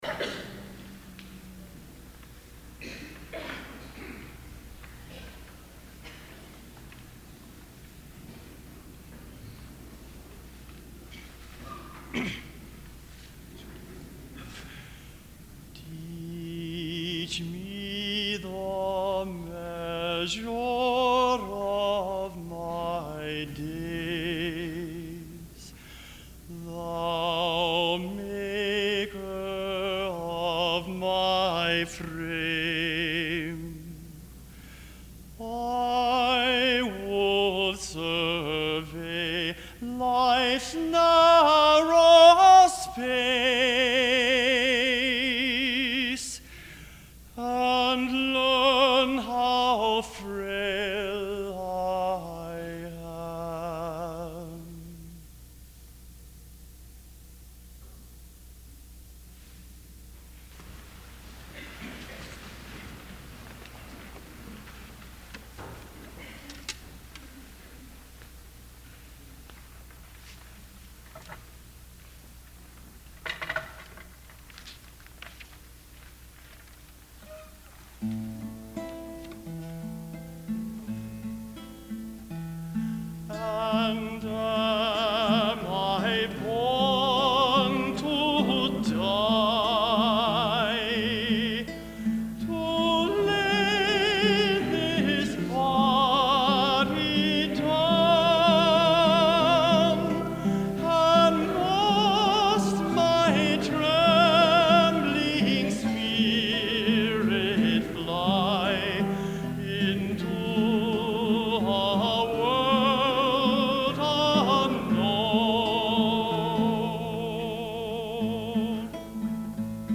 Emory University Worship Service